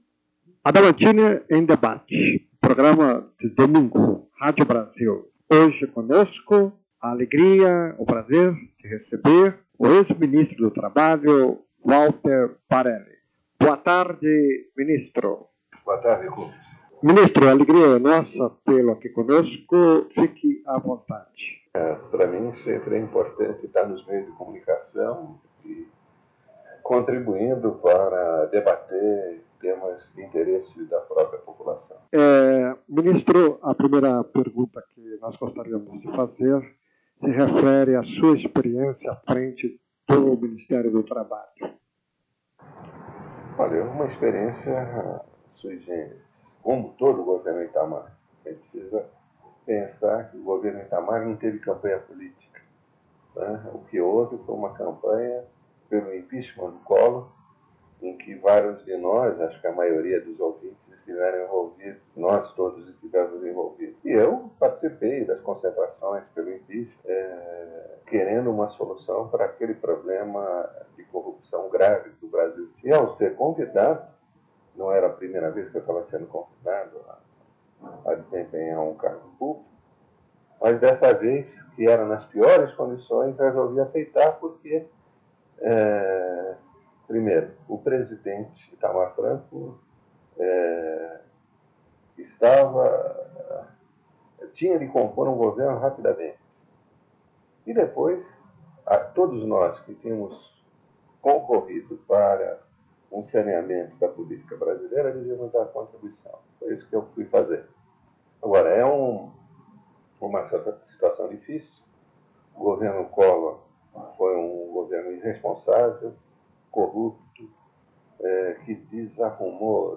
*Recomendado ouvir utilizando fones de ouvido.